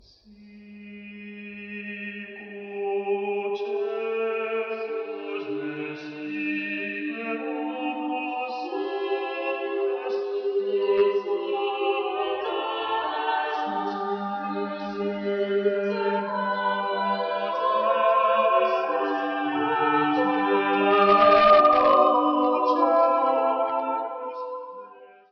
Coro de cámara Coralia
En el segundo ejemplo, tenemos un motete de Palestrina a 4 voces. Las voces van entrando de forma escalonada, como si fuesen persiguiéndose una a la otra, lo que provoca que el texto se  mezcle y sea difícil de seguir.